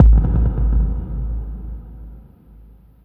45 BD 2   -R.wav